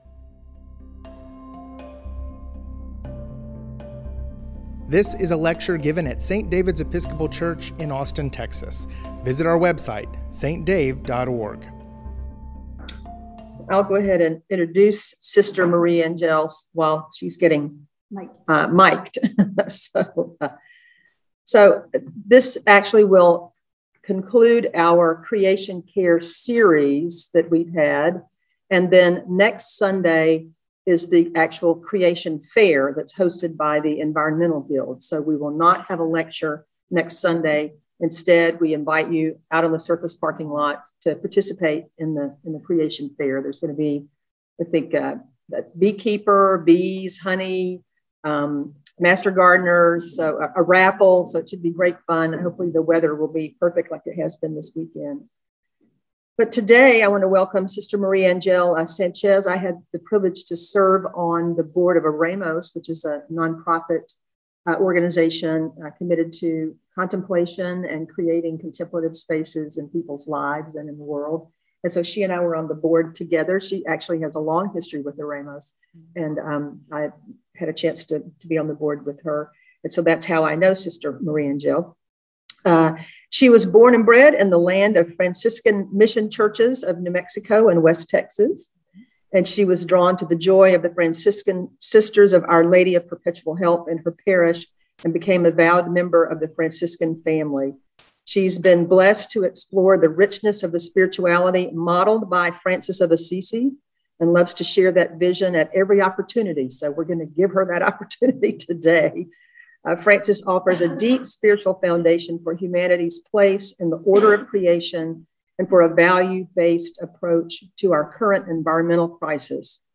Season of Creation Lecture Series: The Life and Teaching of St. Francis